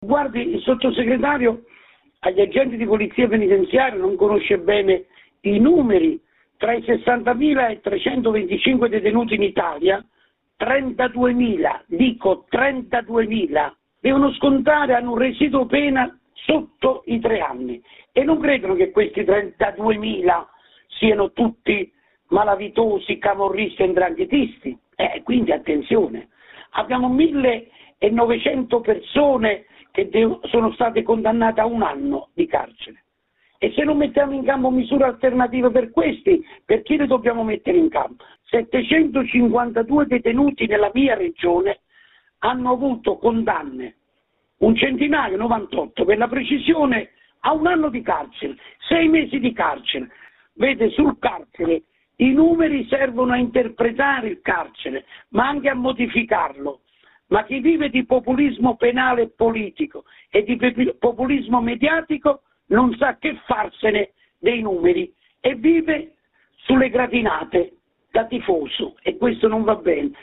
Così risponde a Radio Popolare il Garante dei detenuti della Regione Campania Samuele Ciambriello